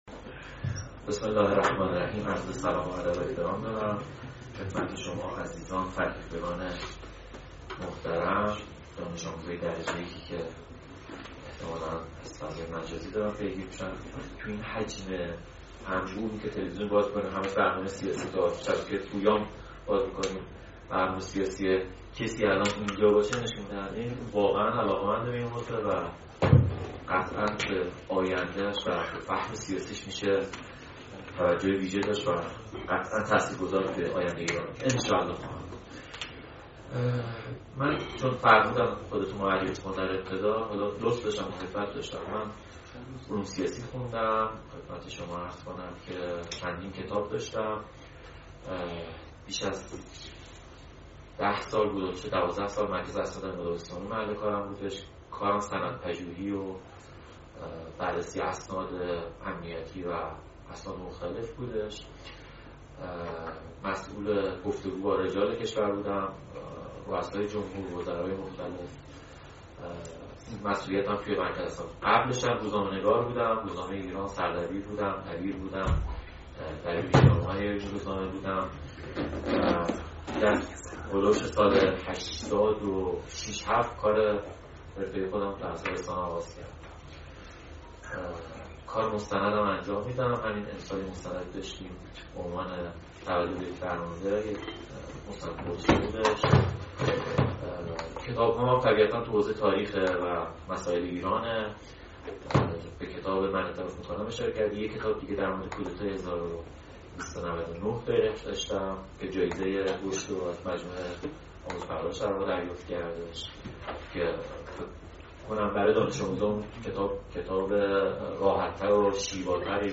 نشست ها